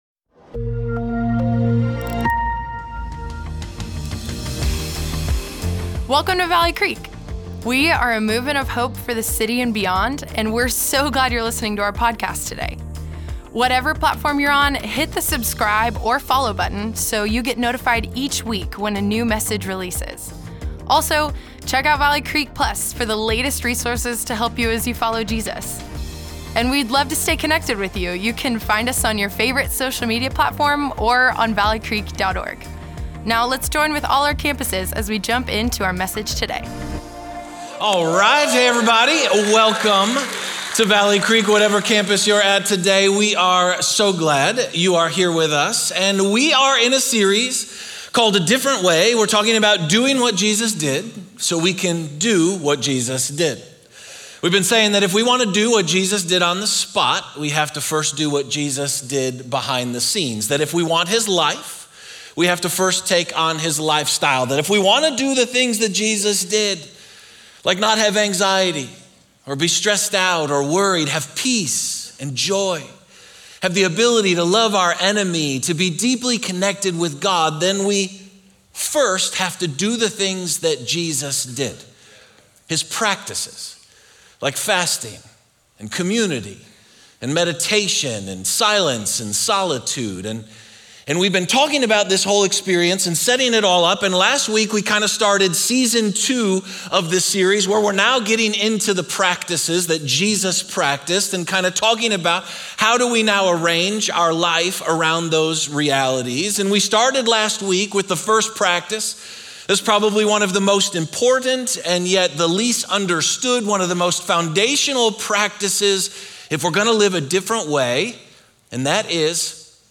Valley Creek Church Weekend Messages Scripture, Part Two Mar 03 2024 | 00:51:58 1x 00:00 / 00:51:58 Subscribe Share Apple Podcasts Spotify Amazon Music Overcast RSS Feed Share Link Embed